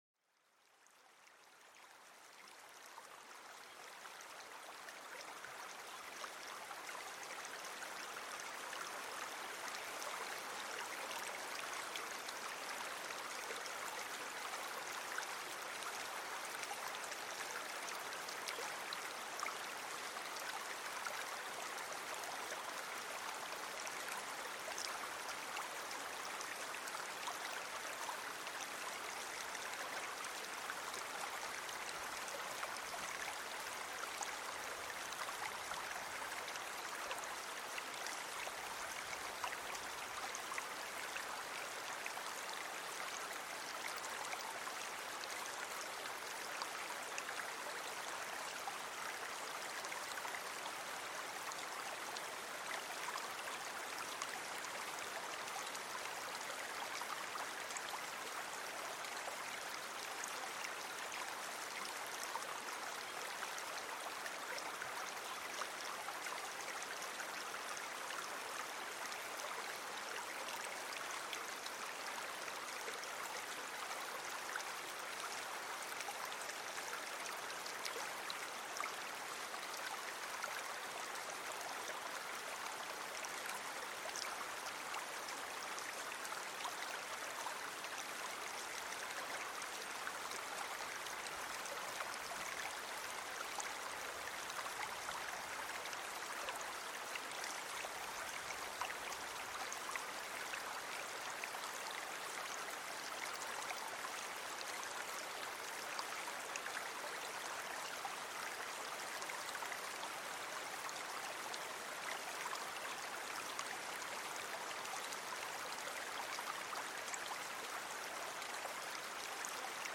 Laissez-vous bercer par le doux murmure d'un cours d'eau traversant une forêt verdoyante. Ce son naturel apaise l'esprit et aide à se détendre après une journée chargée.